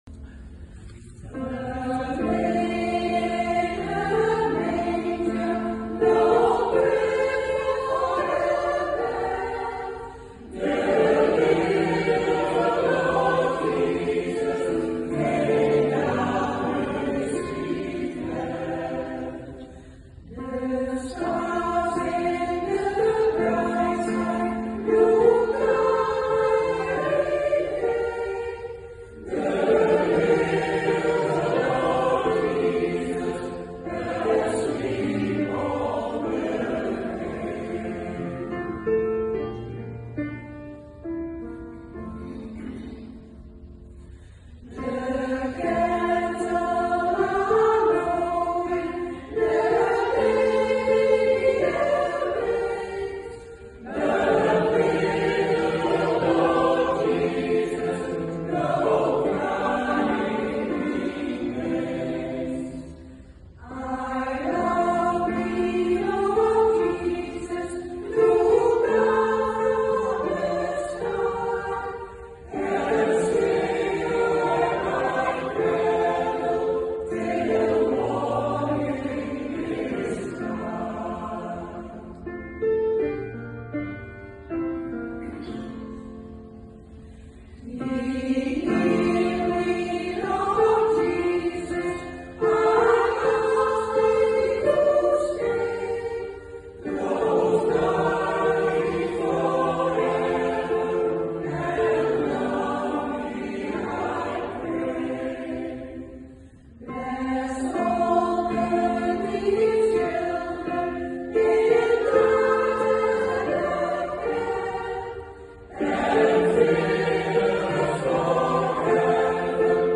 Kaarsenfeest Stevensweert 13 december 2024
Op een aantal locaties in het fraai verlichte dorp treden koren en muziekgezelschappen op. En dit jaar mochten wij daarbij zijn; in de grote kerk zongen we vier kerstliederen en “Look at the world”, “Walking in the air” (uit de film The Snowman) en “Gabriella’s song” (uit de film As it is in heaven).
Una Sono is een gemengde zangvereniging, die in 2023 65 jaar bestaat.